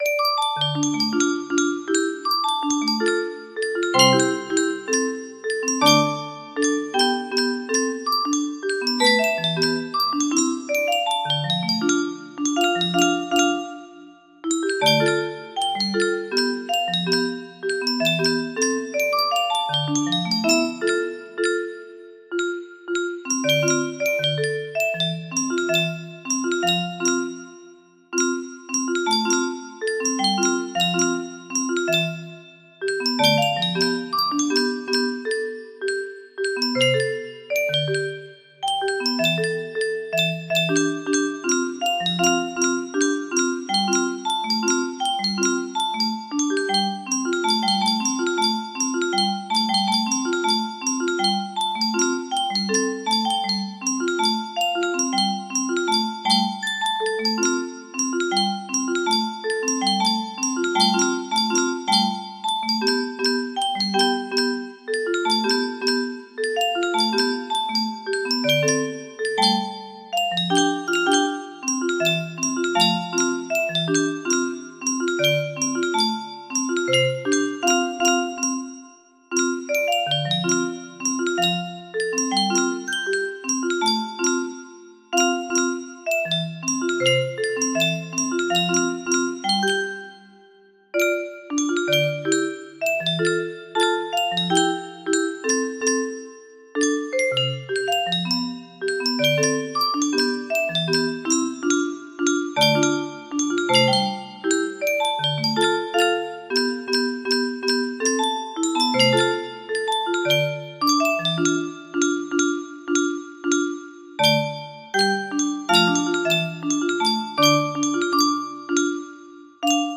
Full range 60
Letter notes In Keynote D (original key)